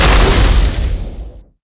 launcher_explode3.wav